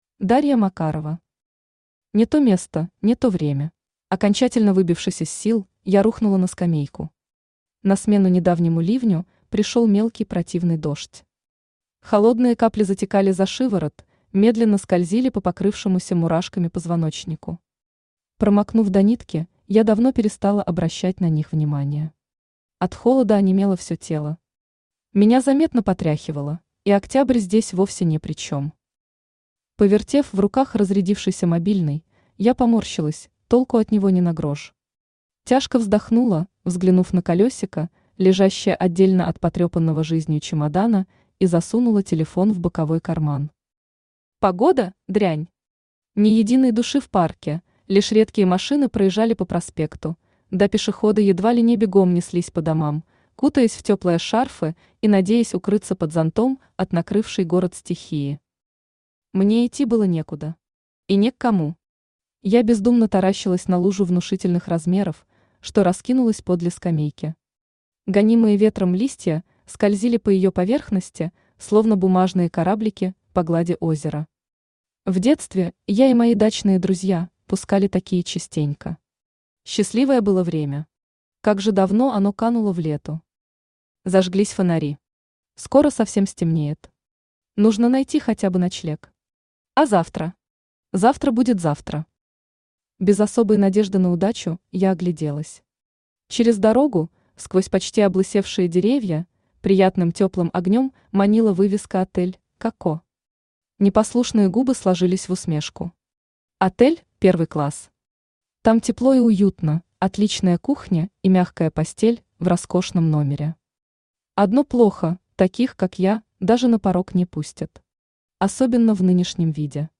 Аудиокнига Не то место, не то время | Библиотека аудиокниг
Aудиокнига Не то место, не то время Автор Дарья Макарова Читает аудиокнигу Авточтец ЛитРес.